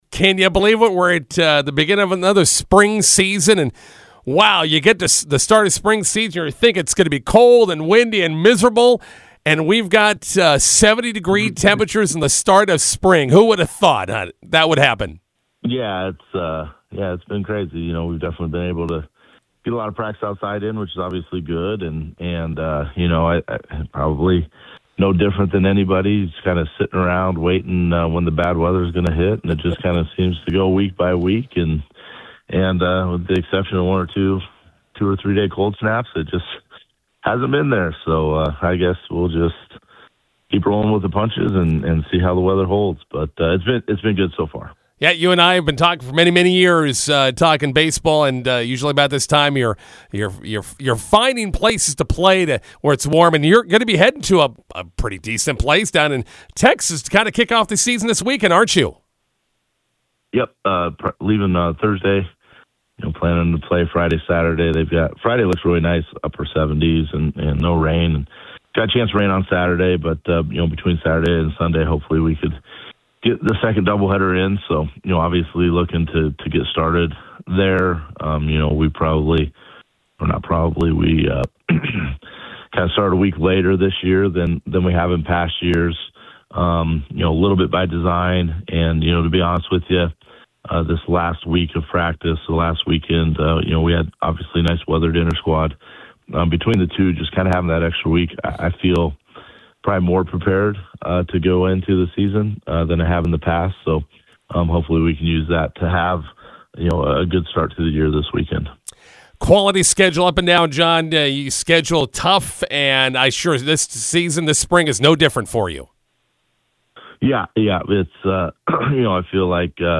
INTERVIEW: McCook Community College Baseball opens spring season this weekend in Texas.